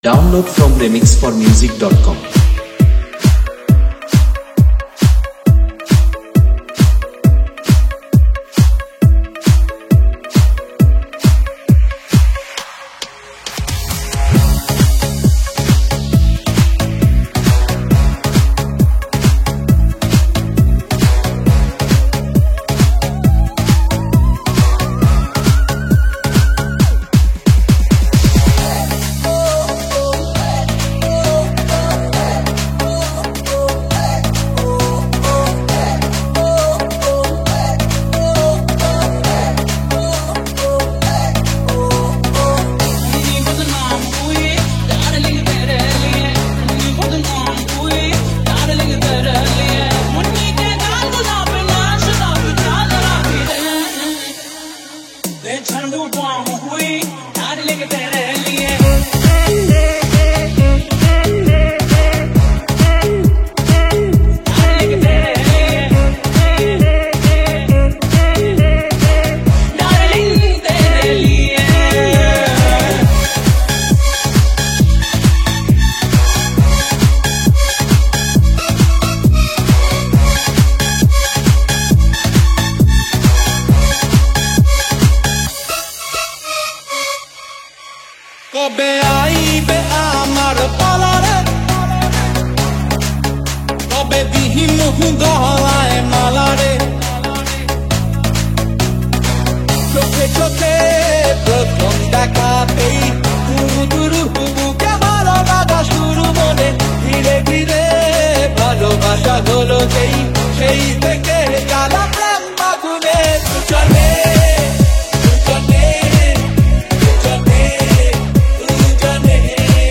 Category : Mashup Remix Song